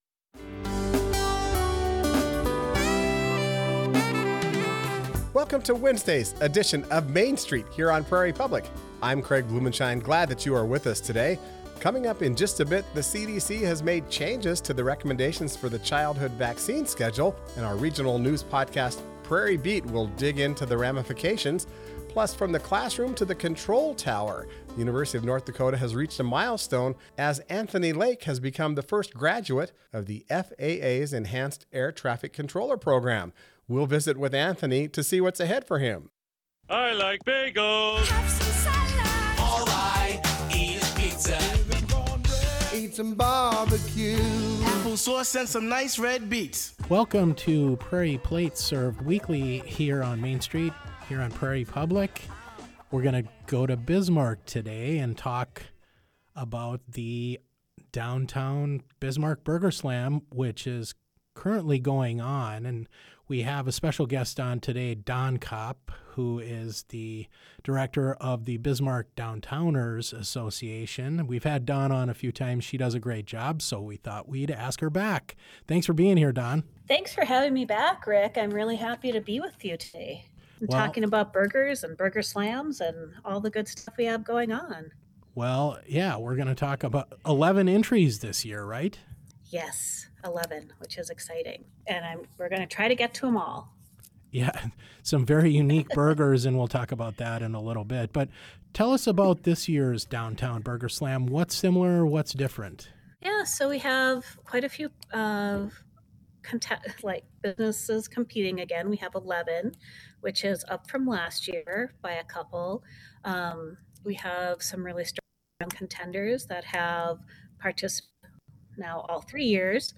Listen for interviews with authors, artists, and newsmakers that tell the story of our region.